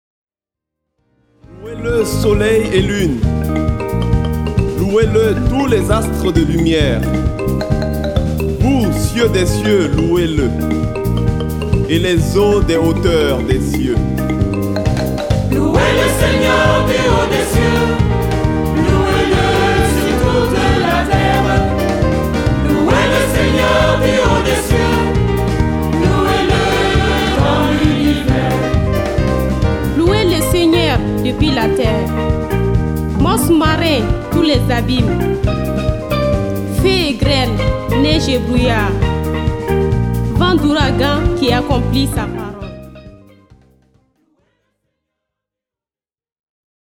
Utilisant des arrangements très colorés
mélodies simples